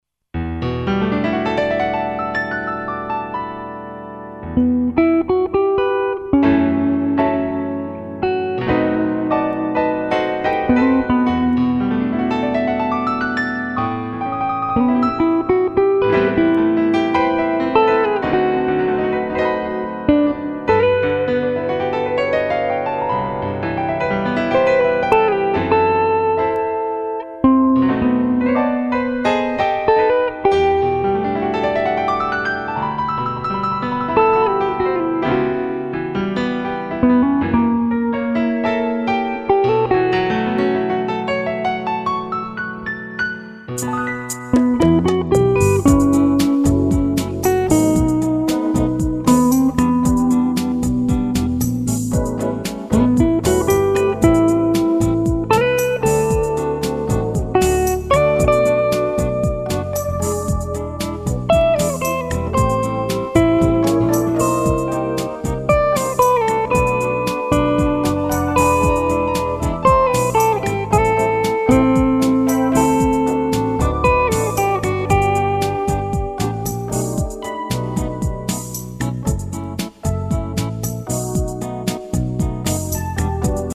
Прошу оценить звучание гитары.